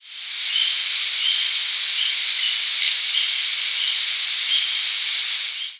Black-capped Chickadee nfc
hypothetical Black-capped Chickadee nocturnal flight calls
Spring Peeper in the background.